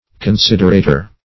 Considerator \Con*sid"er*a`tor\, n. One who considers.